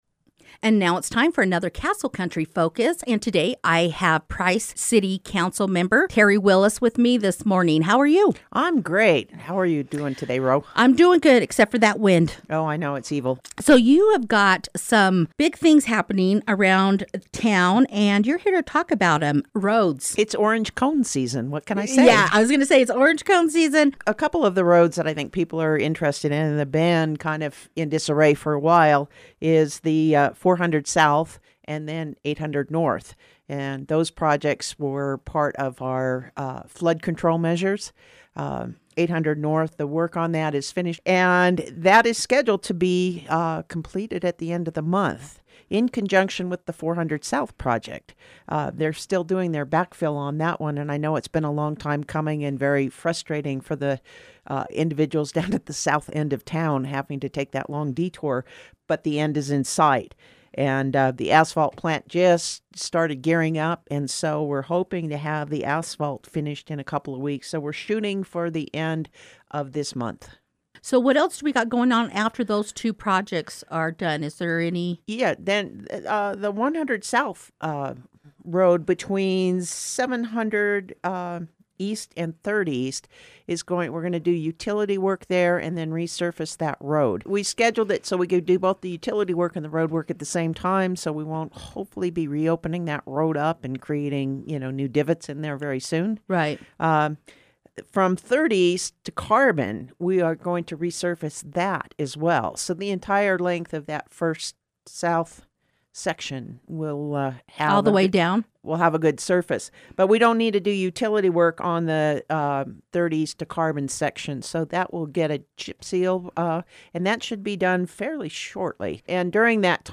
Price City has been working hard on improving the roads around town and will continue to do so during the summer months. Council Member Terry Willis stopped by the Castle Country Radio Station to share the information with listeners.